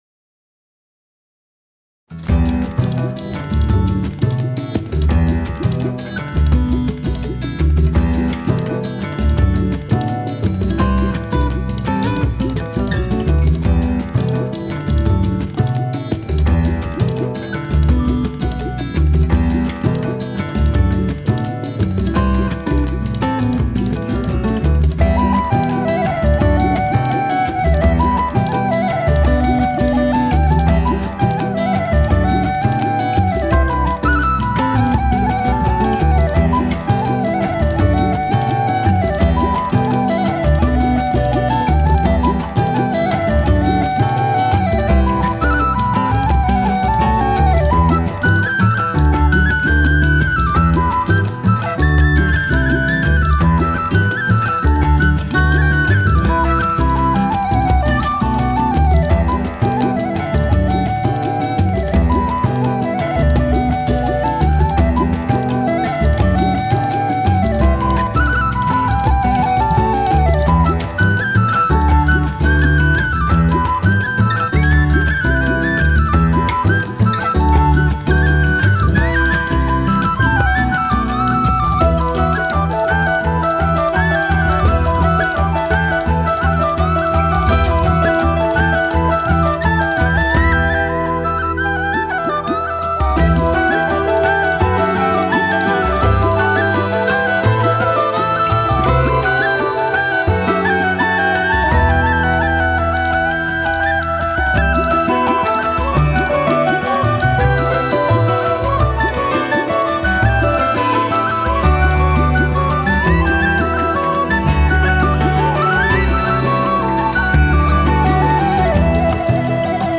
融合afro-beat、techno、东欧、亚洲的音乐格律与西方神秘主义